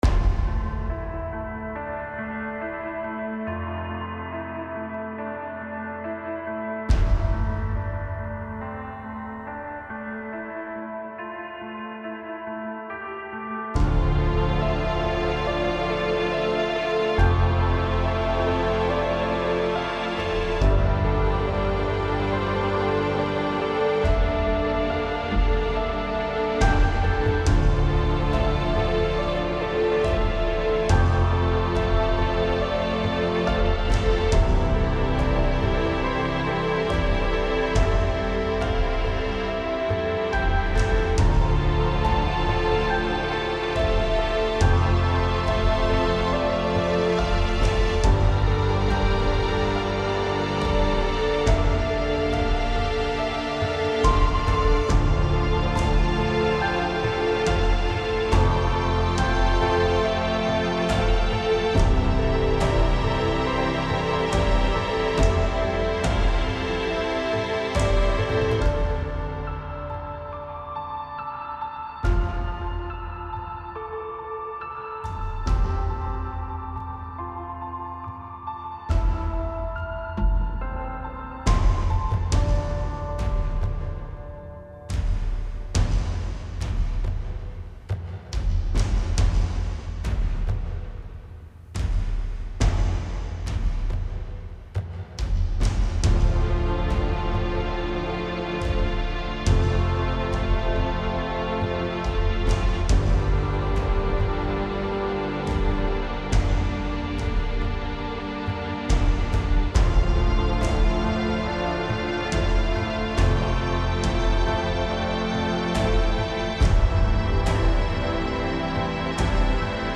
Tempo 140BPM (Allegro)
Genre Cinematic
Type Instrumental
Mood Conflicting (Aggressive/Chilled)
Key D Minor
Rendered at 192kbps, Stereo